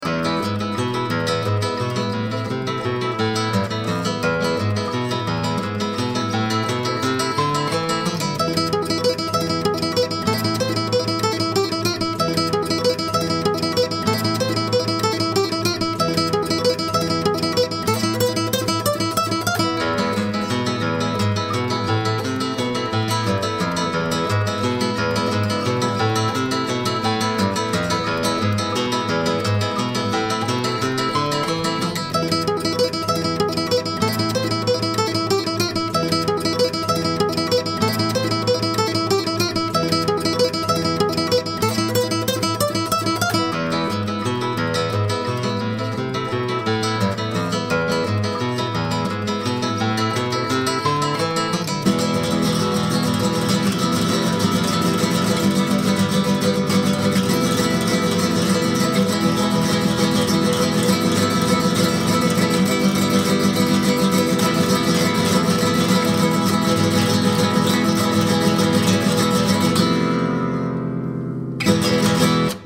Spanish Music